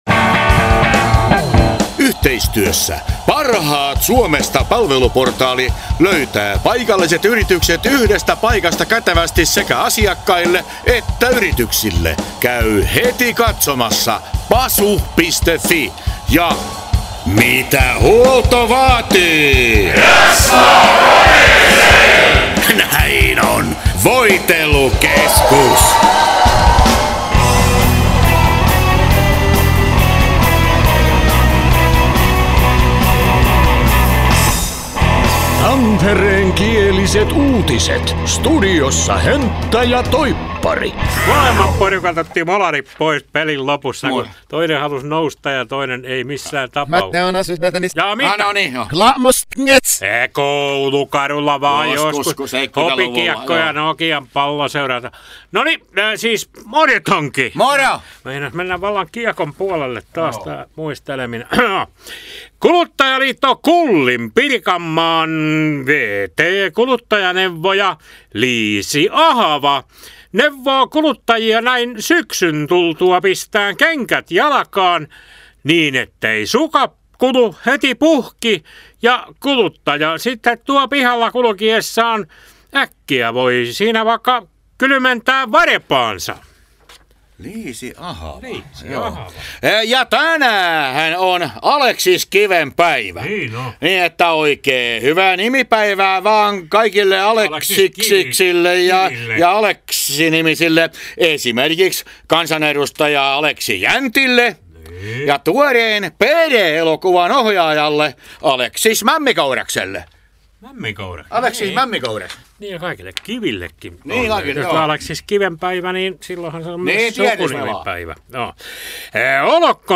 Tampereenkiäliset uutiset